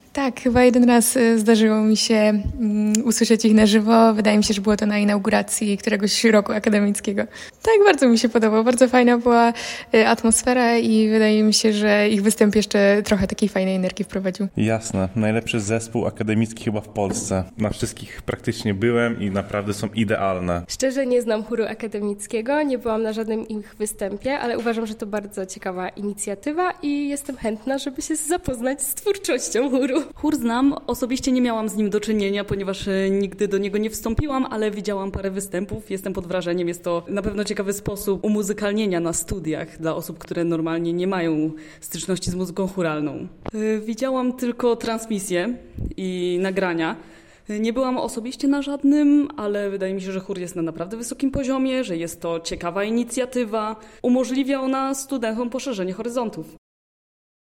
O działalność Chóru zapytaliśmy studentów:
Chor-sonda.mp3